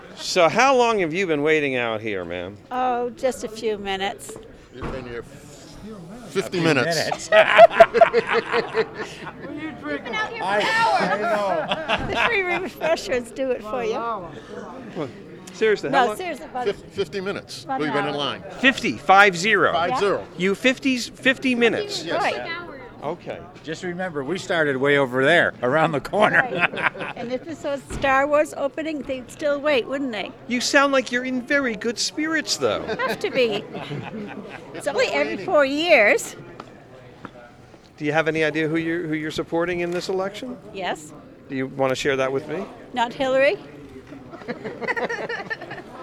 TALKS WITH YORK COUNTY MAINE VOTERS WHO ARE IN VERY GOOD SPIRITS AFTER WAITING ON A LINE 50 MINUTES TO ENTER THEIR CAUCUS: